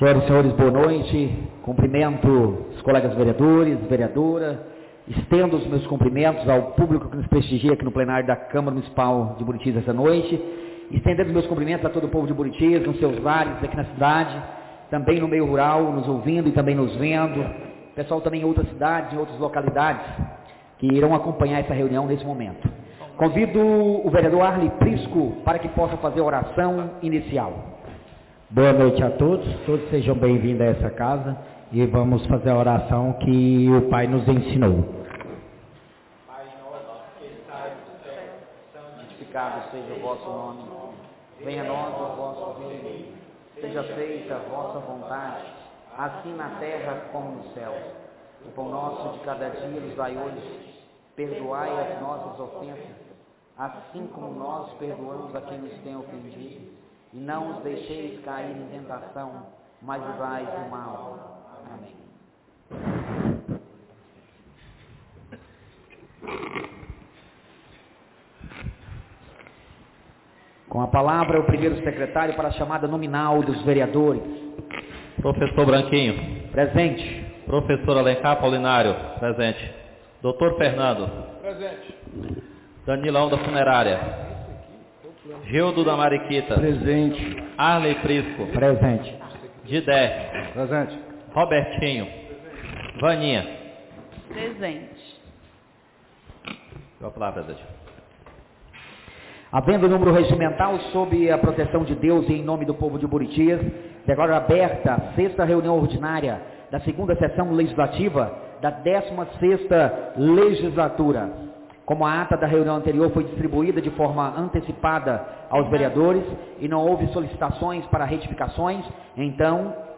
6ª Reunião Ordinária da 2ª Sessão Legislativa da 16ª Legislatura - 09-03-26